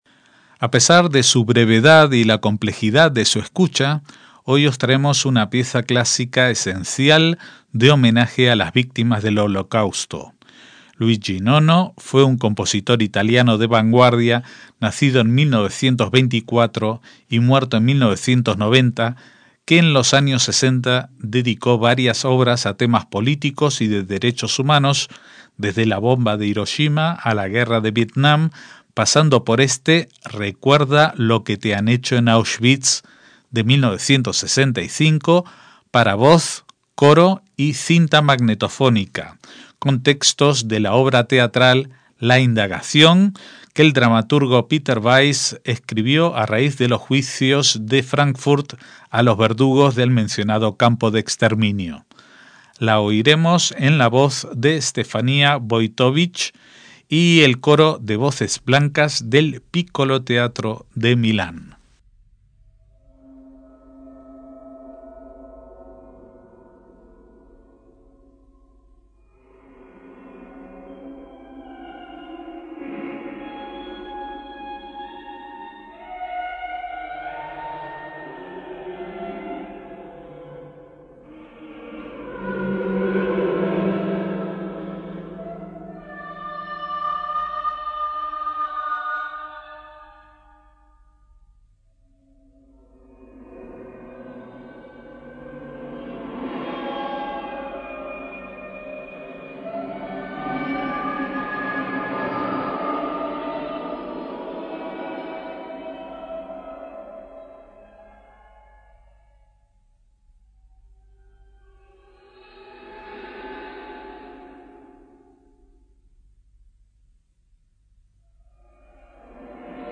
MÚSICA CLÁSICA
una obra electrónica de factura contemporánea
para voz, coro y cinta magnética
soprano